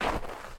footstep_snow0.mp3